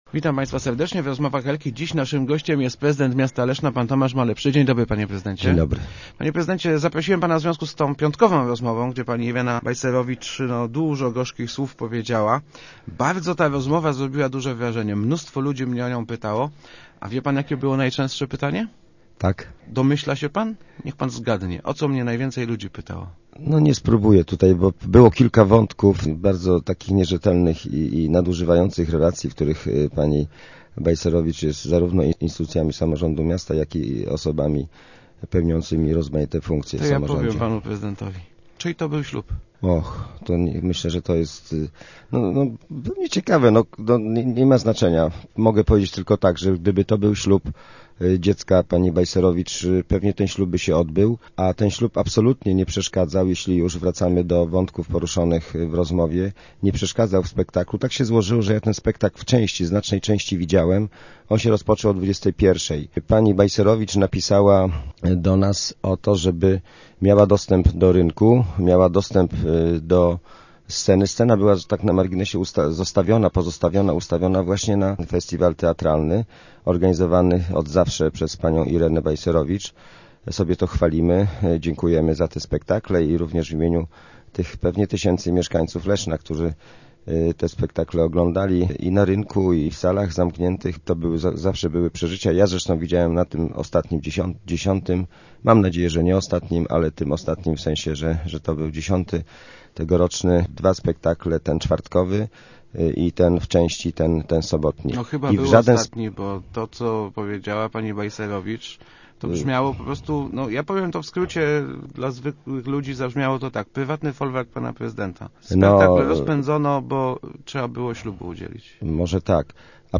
Zapytany, komu udzielał w sobotnie popołudnie ślubu prezydent odparł, że akurat to nie ma żadnego znaczenia.